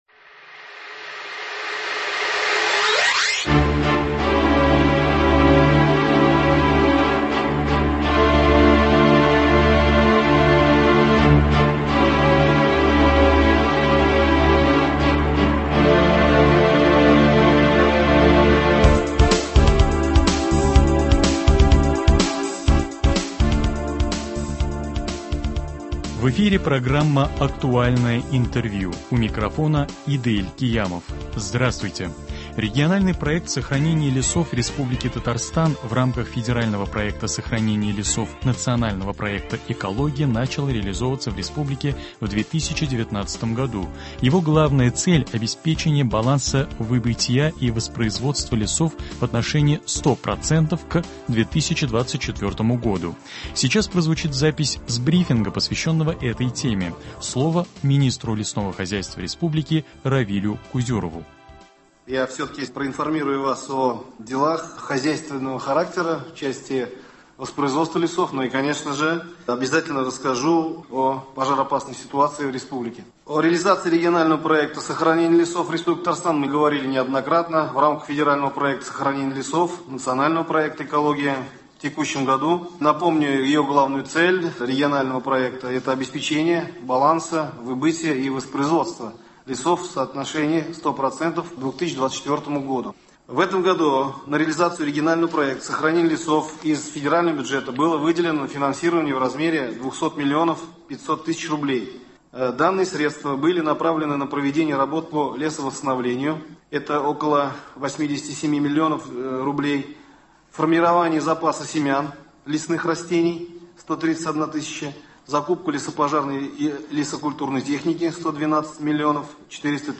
Актуальное интервью (25.08.2021)